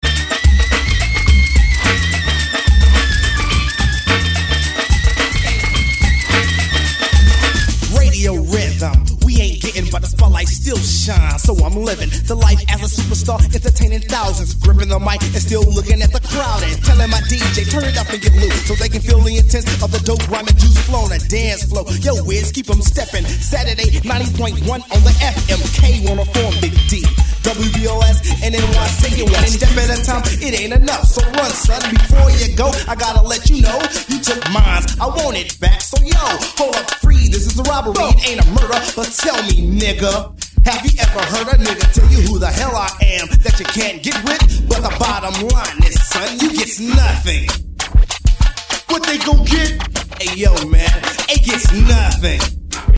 Mega rare tape version
gangsta rap